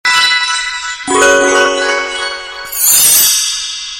SFX小魔仙挥动魔法棒的声音音效下载
SFX音效